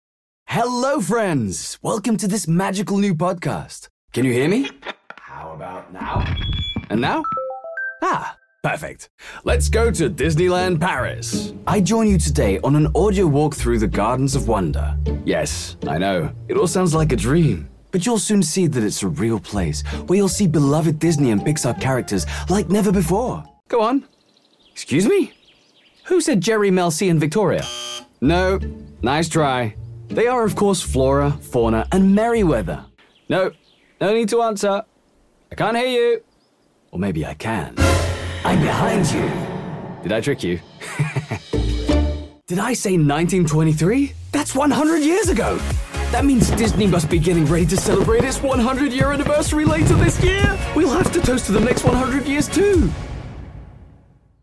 MALE VOICE OVER DEMOS AND EXTRACTS